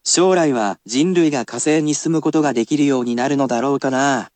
And, again, I will be there to read it aloud for you.